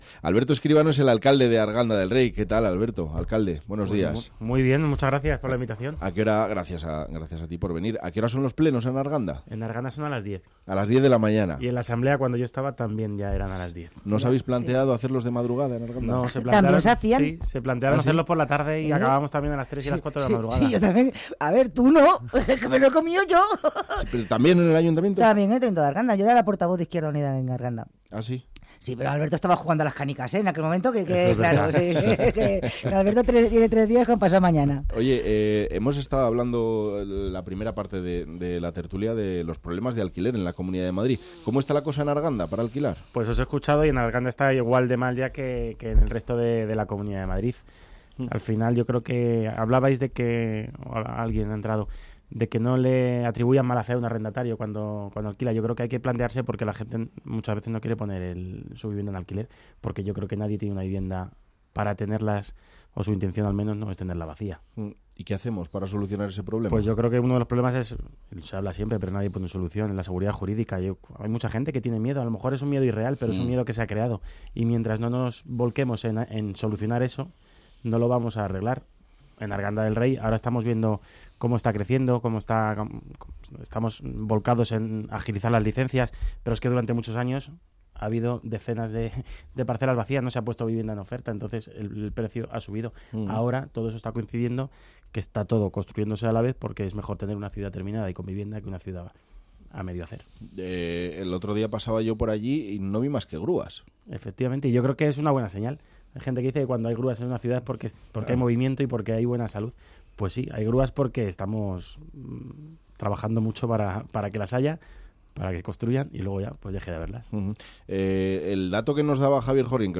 El alcalde de Arganda del Rey, Alberto Escribano, ha pasado por Buenos Días Madrid de Onda Madrid para analizar algunos de los asuntos que más preocupan e interesan a los vecinos de esta localidad del sureste de la región que ha experimentado un importante crecimiento en las últimas dos décadas al pasar de 30.000 habitantes en el año 2000 a los casi 60.000 que hay censados en la actualidad.